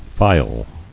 [phy·le]